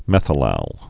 (mĕthə-lăl)